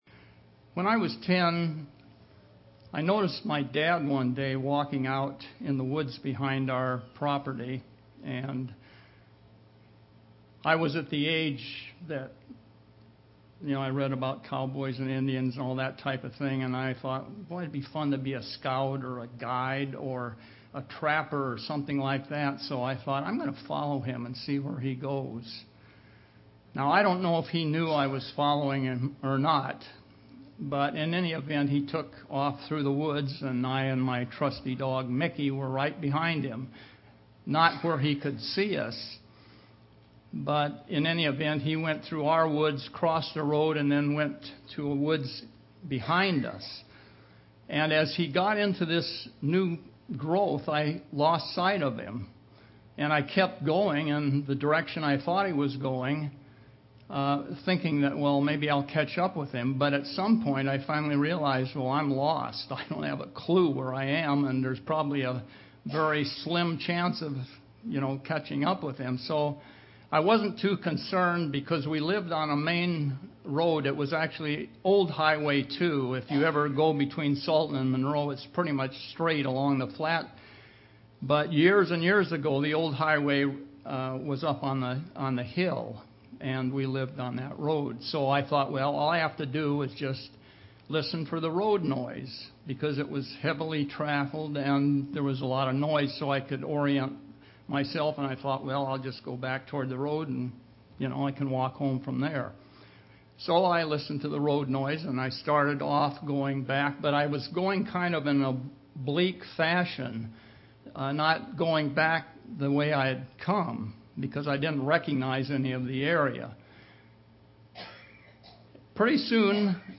Given in Seattle, WA
UCG Sermon Studying the bible?